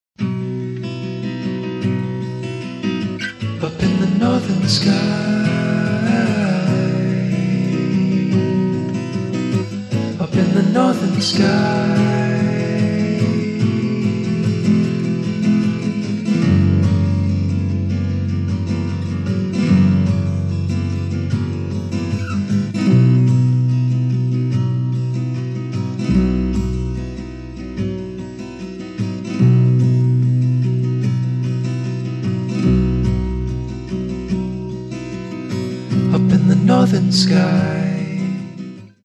Progressive Rock
デビュー作とは思えないほど、ファンキーかつハードなグルーヴを生み出している。